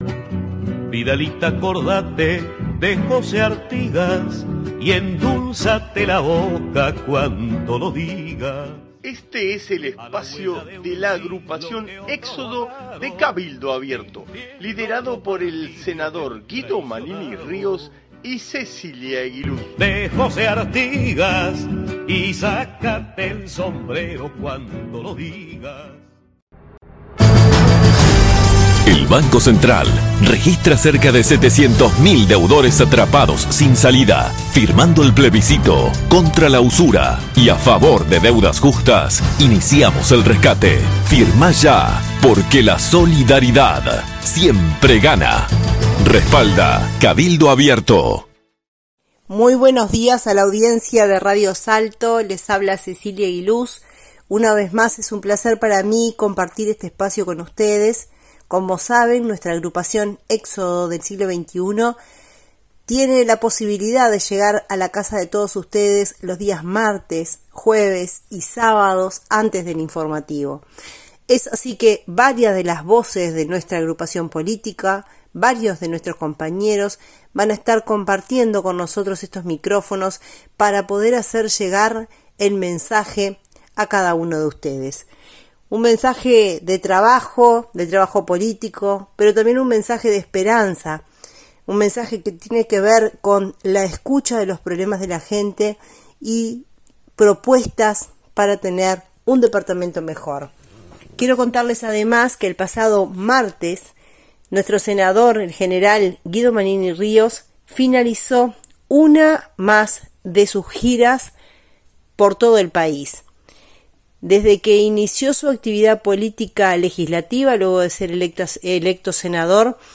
Audición radial de nuestra agrupación para Radio Salto(1120AM) del día 7 de diciembre de 2023.